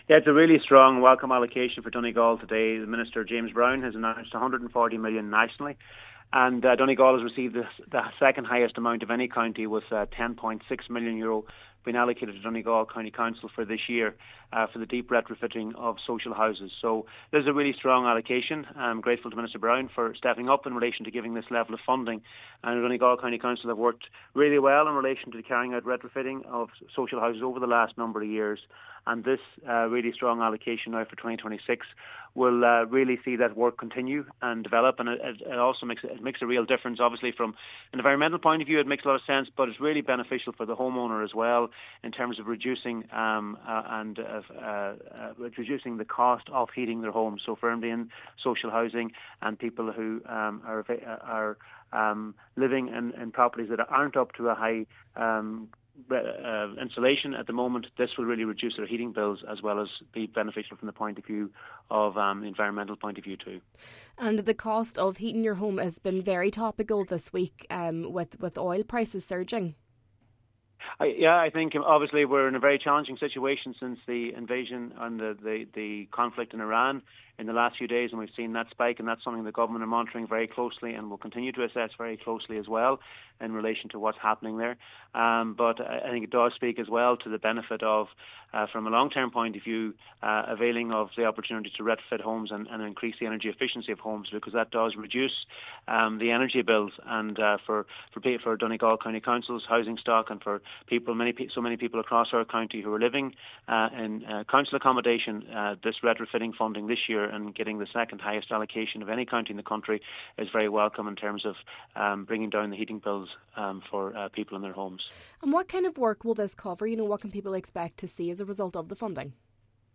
Minister of State Charlie McConalogue says with ongoing pressure on household energy costs, measures like this are particularly welcome: